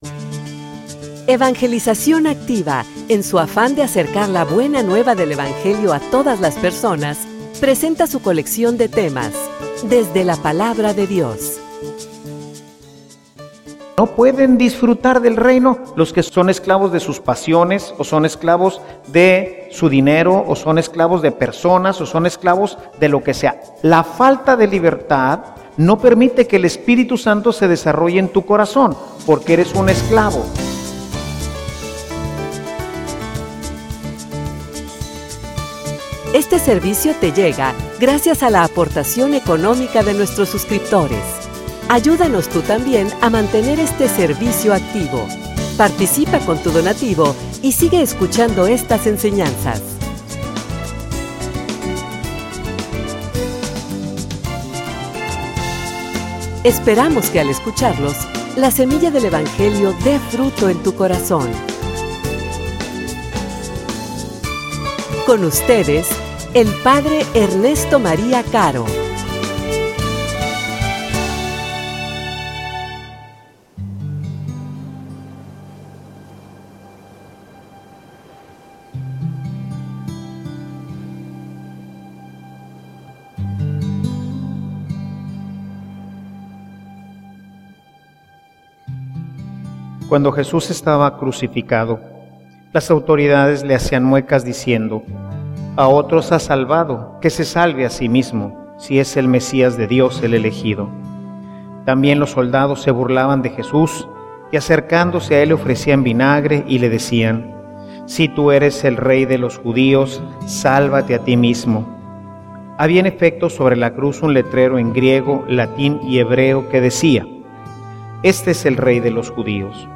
homilia_Disfruta_el_reino.mp3